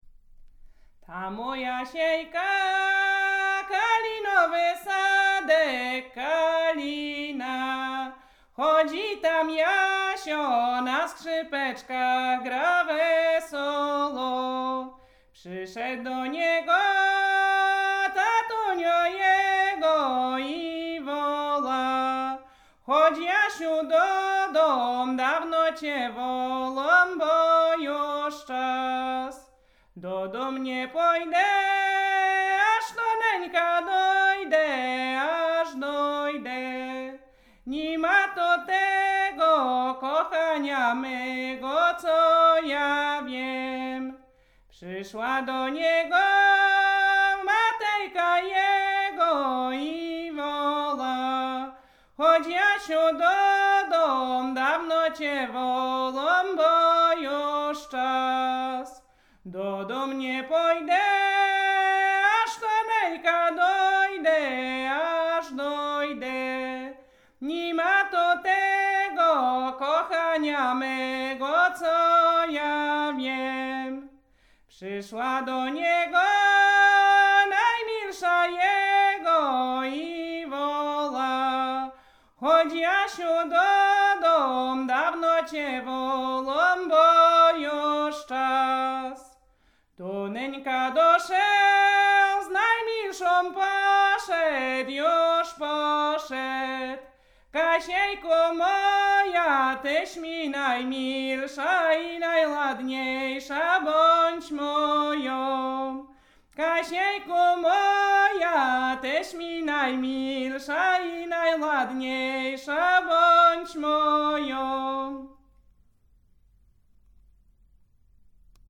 Dunajowa
do panny dunajowe gody kolędowanie kolędy zima kolędy życzące